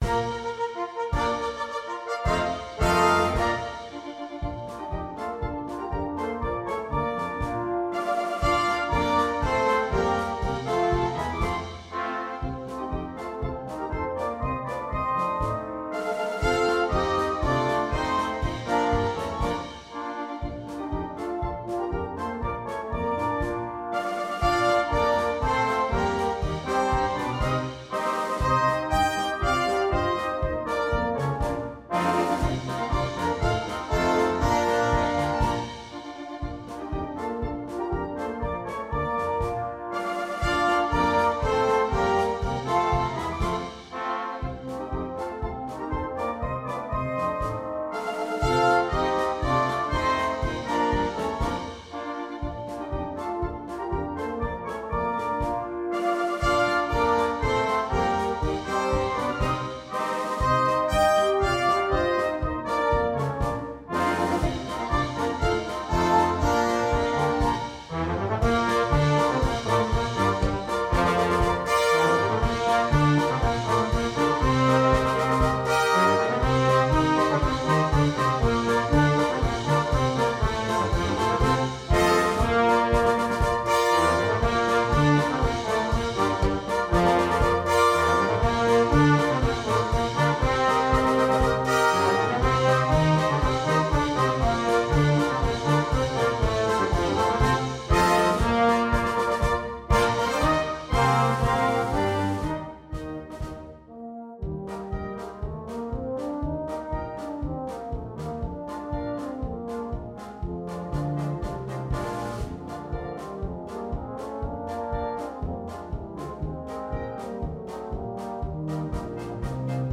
2. Blaskapelle
komplette Besetzung
ohne Soloinstrument